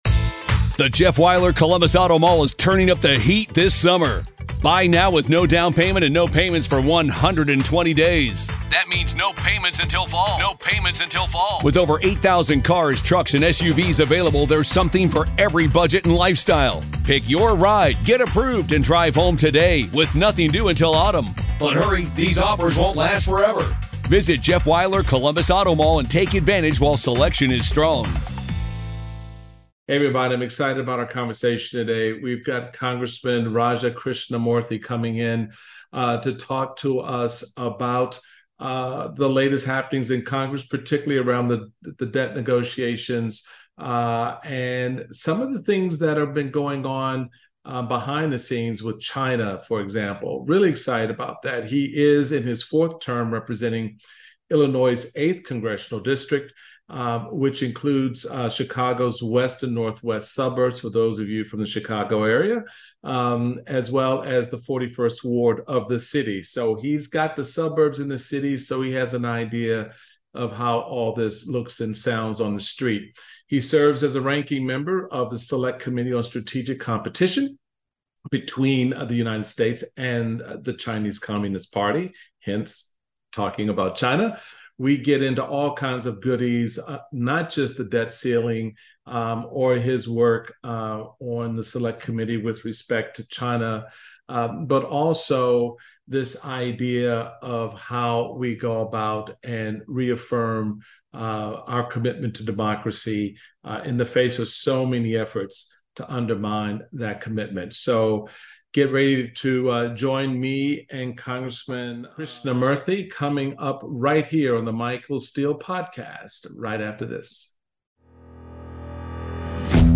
Michael Steele speaks with Congressman Raja Krishnamoorthi. The pair discuss the debt ceiling deal, how bipartisanship can succeed, getting through the culture wars and the United States' relationship with China.